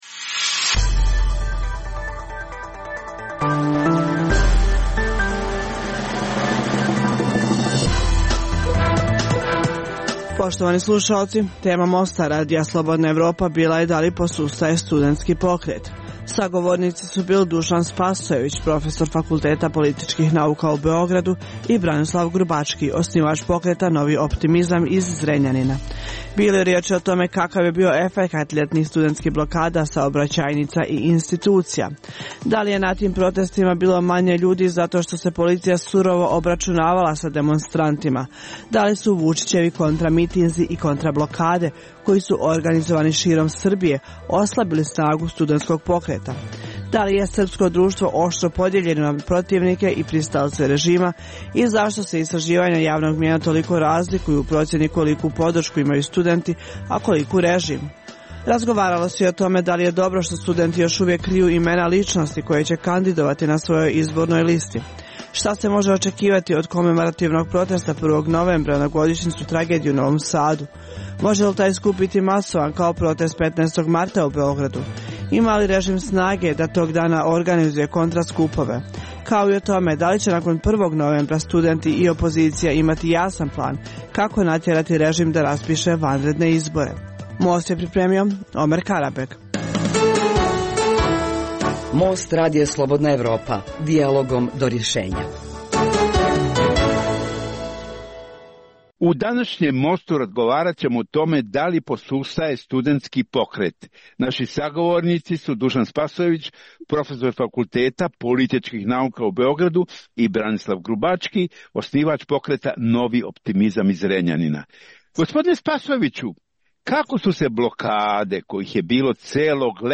Emisija o putu BiH ka Evropskoj uniji i NATO sadrži vijesti, analize, reportaže i druge sadržaje o procesu integracije.